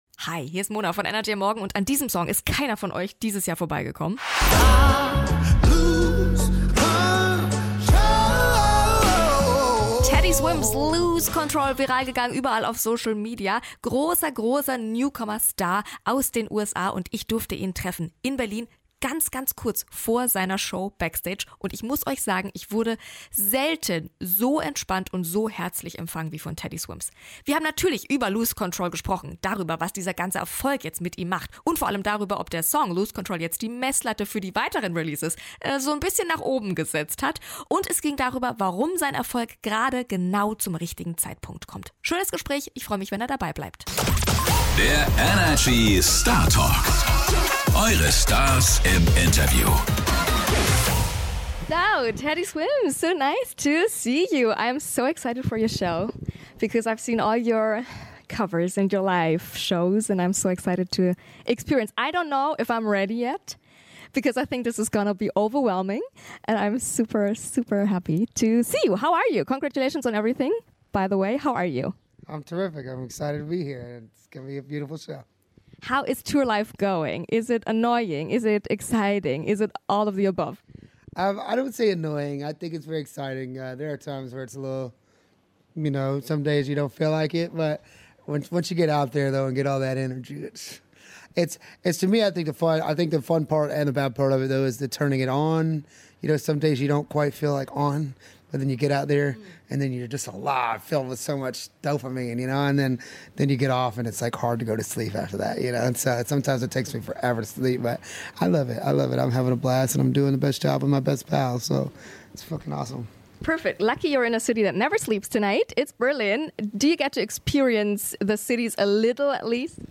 Kurz vor seiner Show hat er sich die Zeit für uns genommen und uns unter Anderem erklärt, warum der Song zu genau dem richtigen Moment released wurde und warum er sich auf Bühnen immer wie zu Hause fühlt.